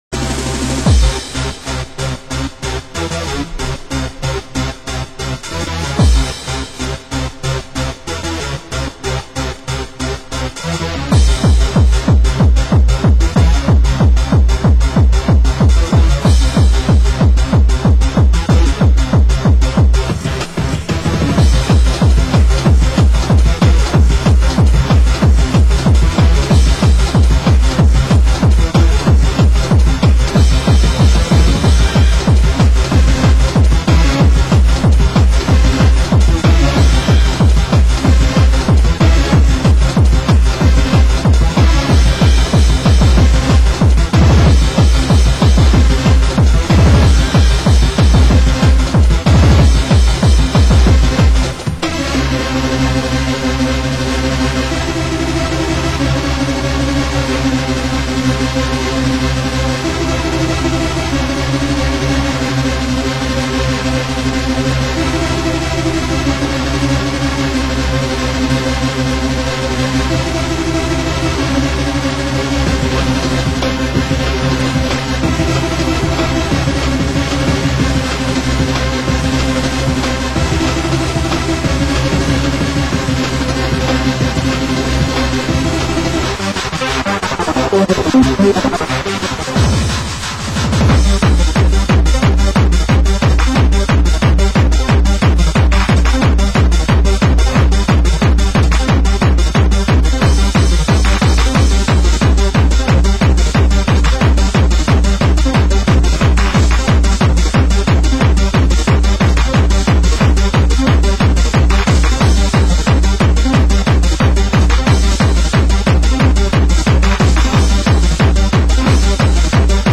Genre: Happy Hardcore